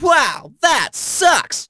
brawl-stars-assets/14.100/sfx/poco_kill_02.wav at a27e75380b2a04a813a01b061037ed7ea8cea076
poco_kill_02.wav